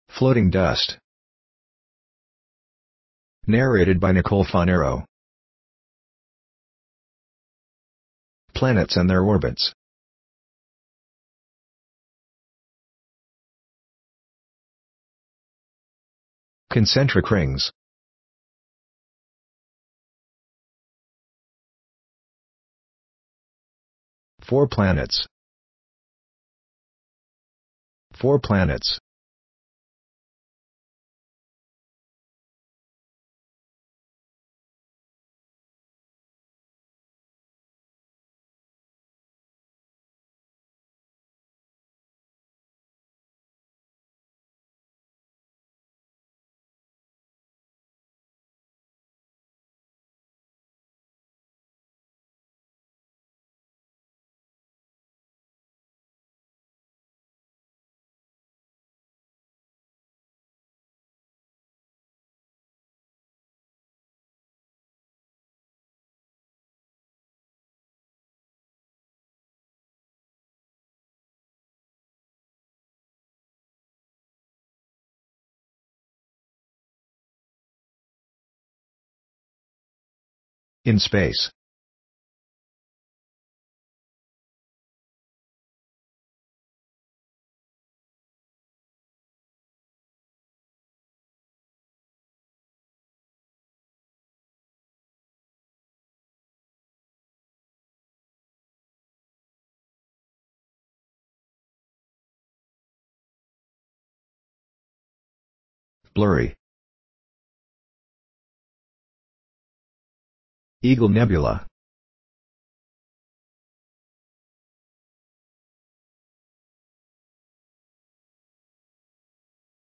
• Audio Description
Sound effects Credit
Audio Description.mp3